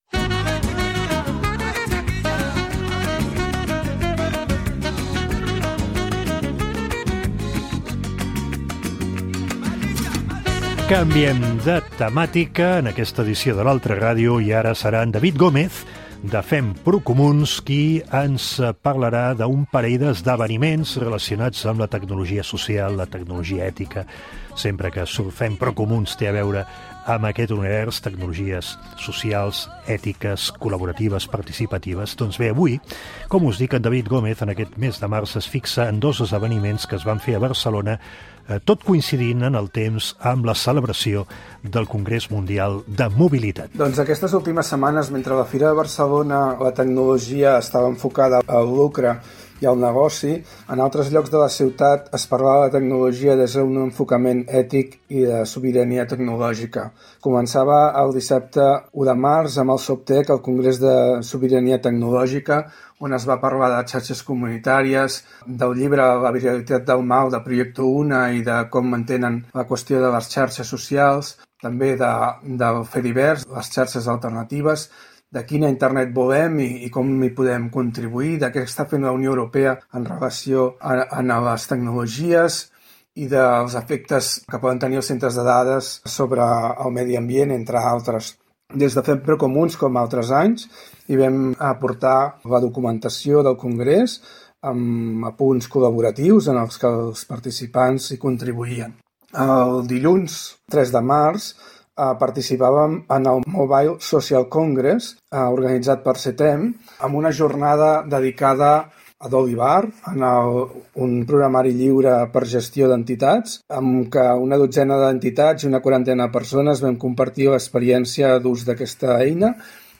El mes de març, en la nostra intervenció al programa L’Altra Ràdio, de Ràdio 4, ens vam fixar en dos esdeveniments que es van fer a Barcelona, tot coincidint en el temps amb la celebració del Congrés Mundial de Mobilitat.